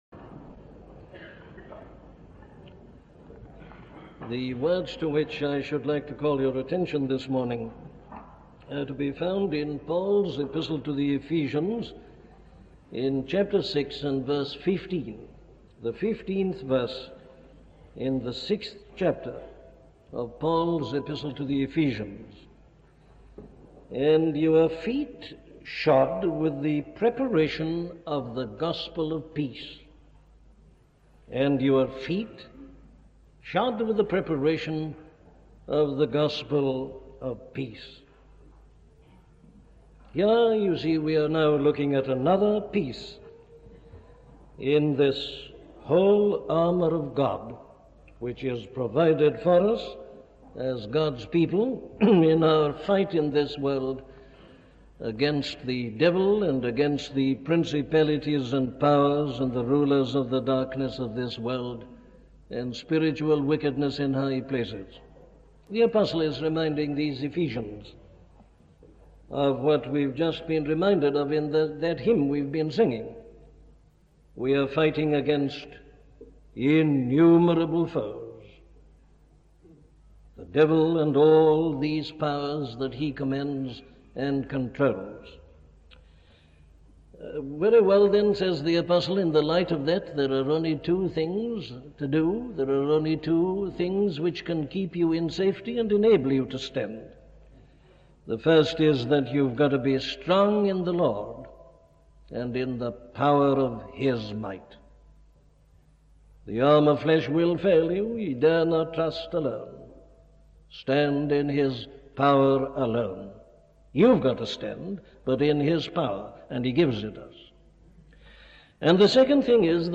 Stand Fast - a sermon from Dr. Martyn Lloyd Jones